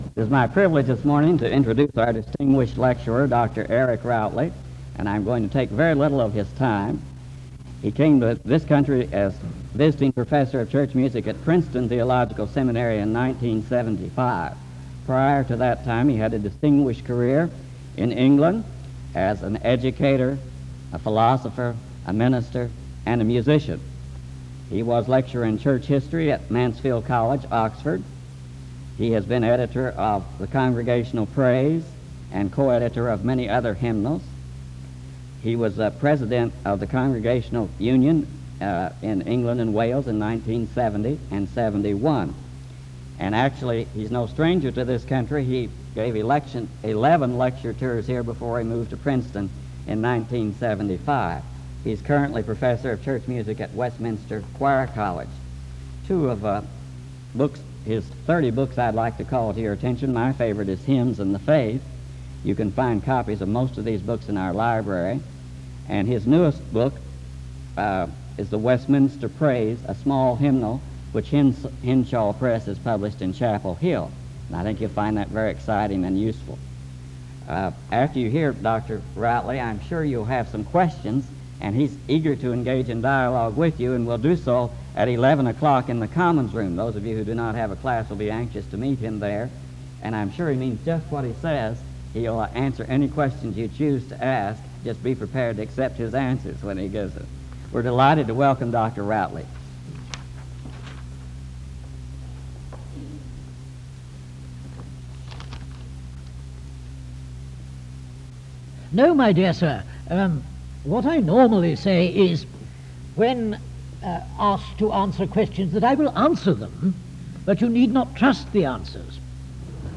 Home SEBTS Spring Lecture